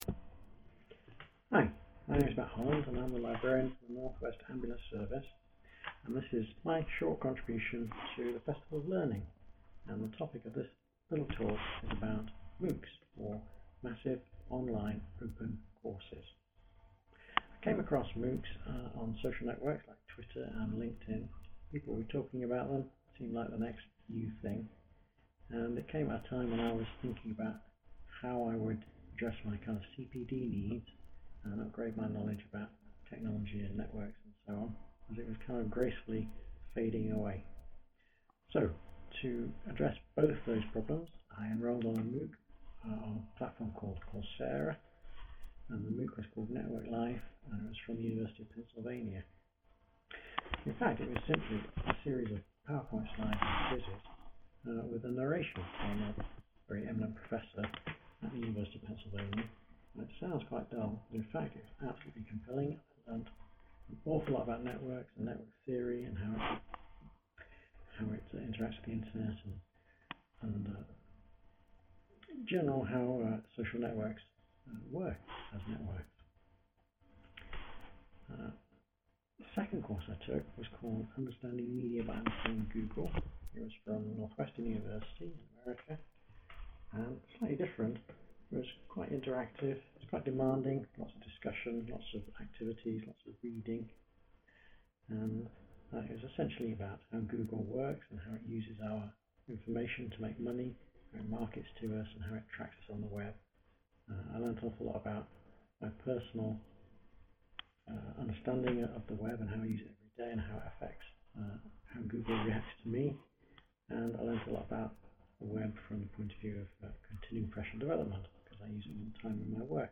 A short talk about my personal experience of MOOCs, why I took them and what I got out of them.